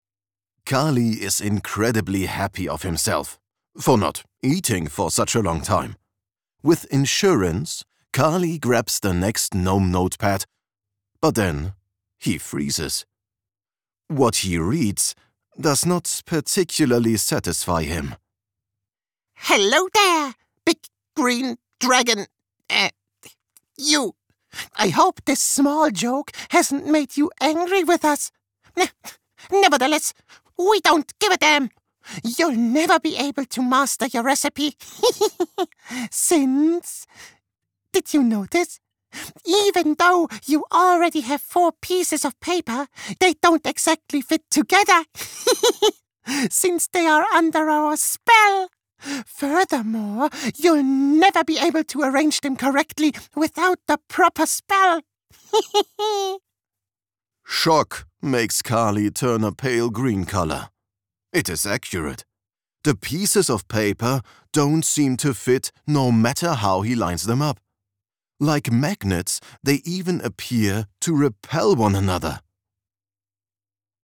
Foreign Language Voice Samples
Commercial Demo
TLM 103 + Sennheiser MKH416
BaritoneBass